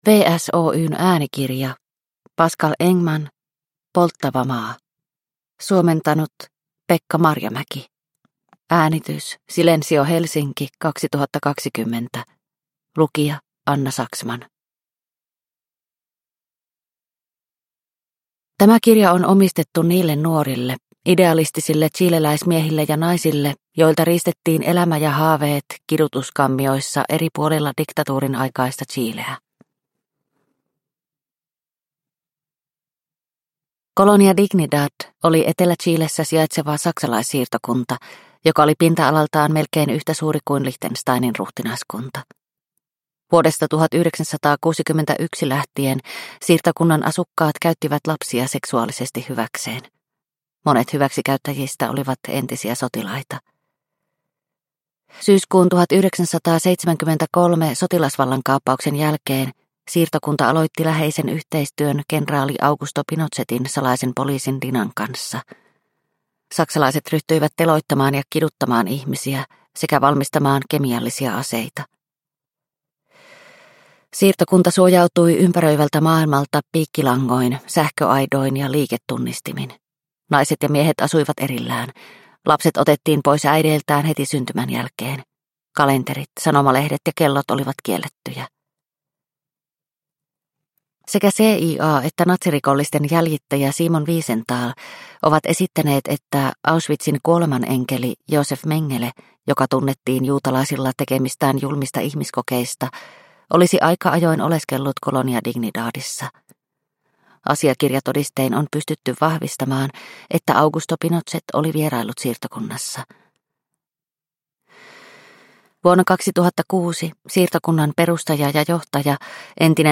Polttava maa – Ljudbok – Laddas ner